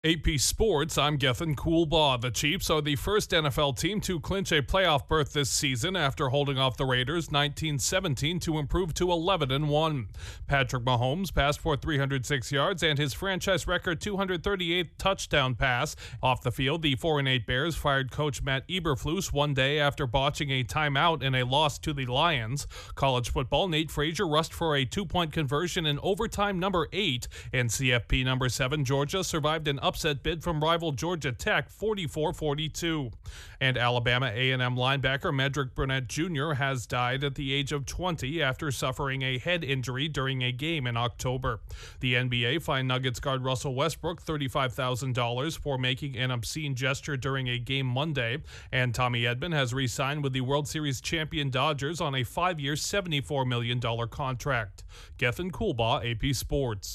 Correspondent
Latest sports headlines